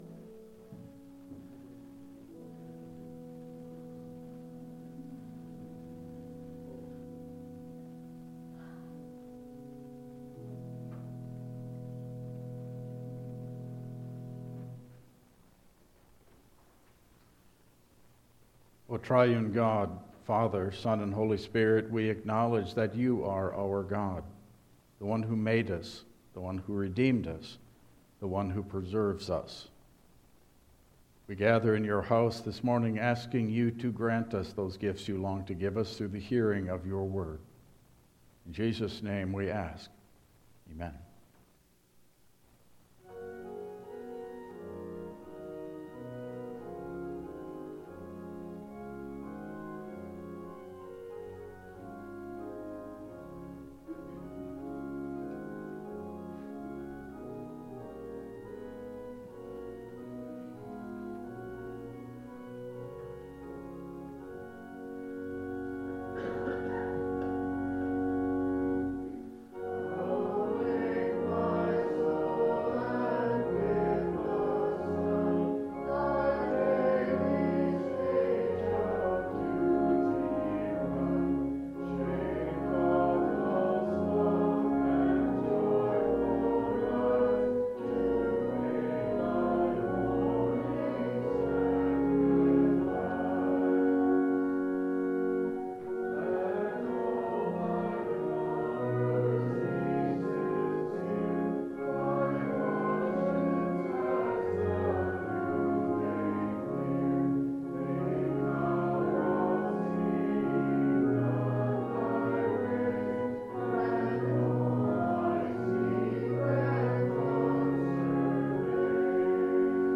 Passage: John 6:56-69 Service Type: Regular Service